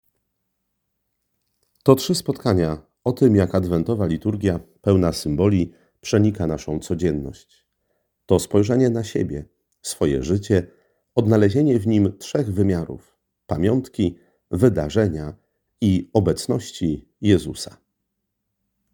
Tak o rekolekcjach mówi